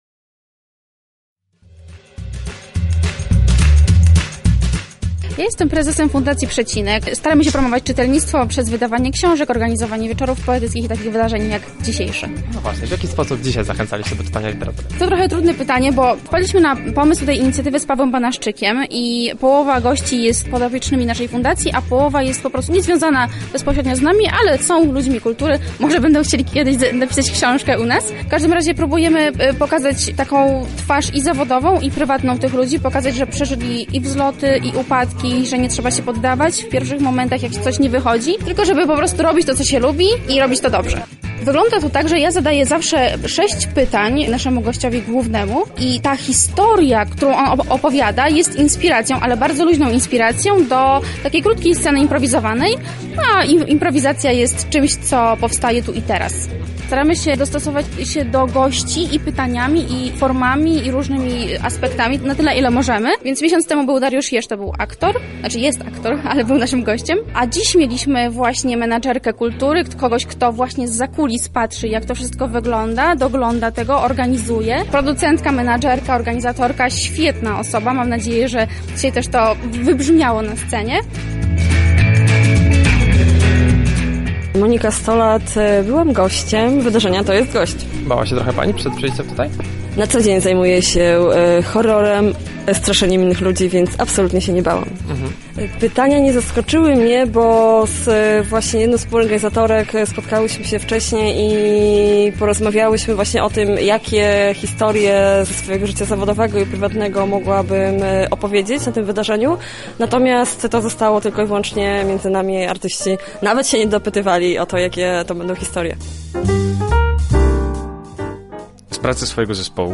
a także kobietę sukcesu. 13. lutego w Chatce Żaka na miejscu był nasz reporter.